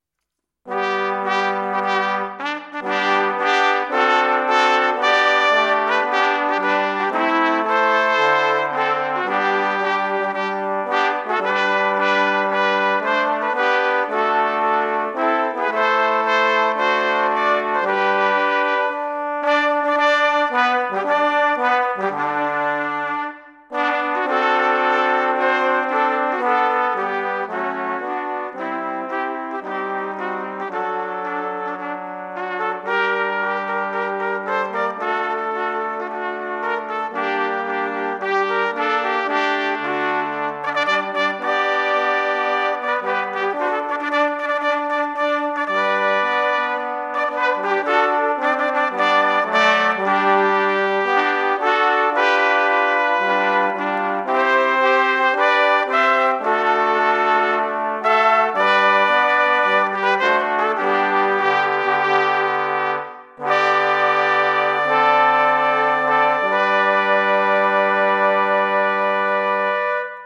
Free Trumpet Sheet Music- “La Marseillaise 11/13/15”- Brass Quartet
La Marseillaise- Trombone 1
La Marseillaise- Trumpet 1